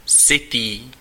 Ääntäminen
IPA: /vɔl/